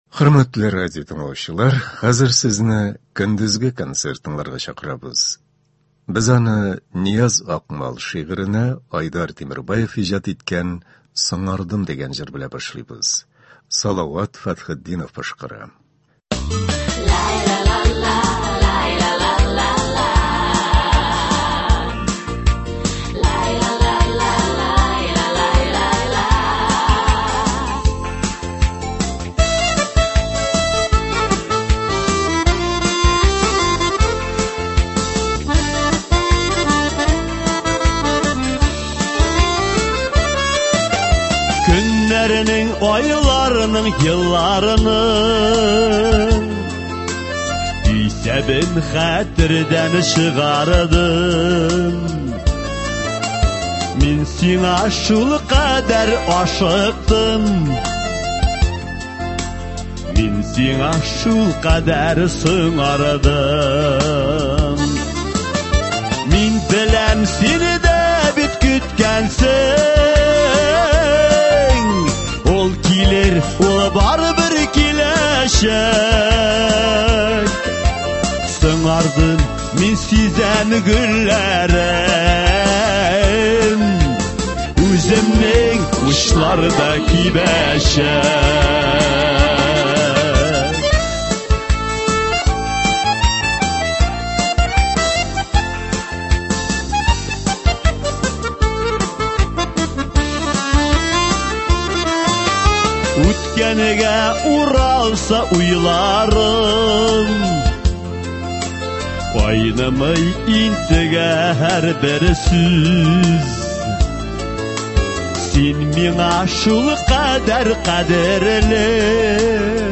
Көндезге эфирны концерт белән дәвам иттерәбез.